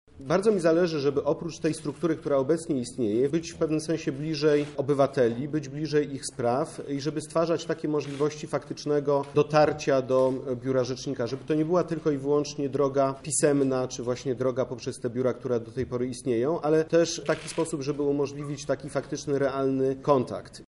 – wyjaśnia Rzecznik Praw Obywatelskich, Adam Bodnar.